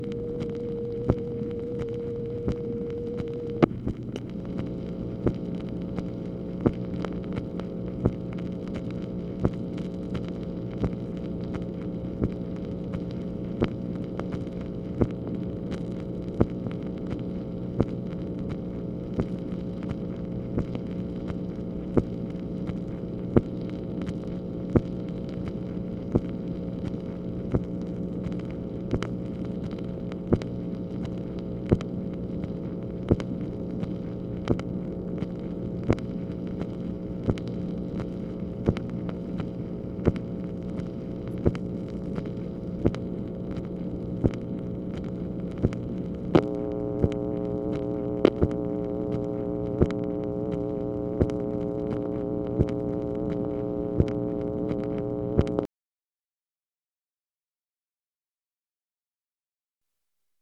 MACHINE NOISE, February 15, 1965
Secret White House Tapes | Lyndon B. Johnson Presidency